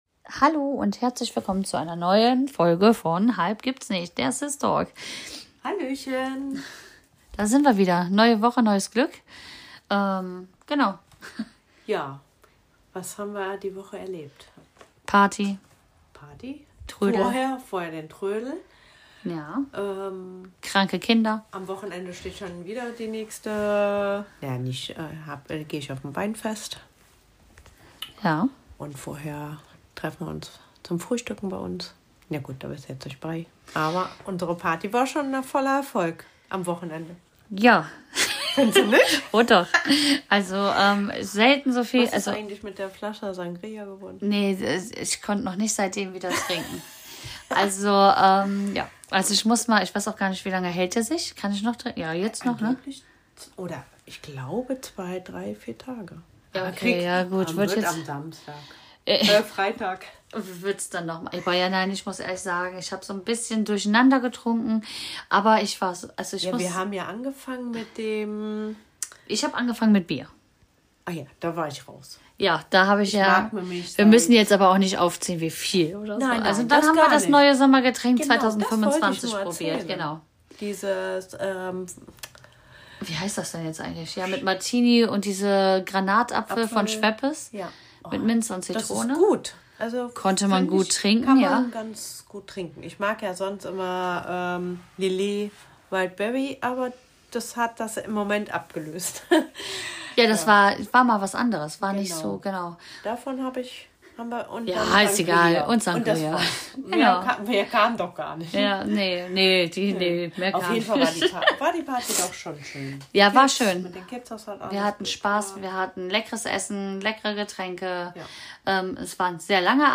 In dieser Folge plaudern zwei Schwestern ganz entspannt über die kleinen und großen Themen des Familienalltags. Sie berichten vom letzten Kindertrödel, tauschen Erfahrungen rund um Geburten aus und sprechen über die Organisation und den Trubel einer Geburtstagsfeier.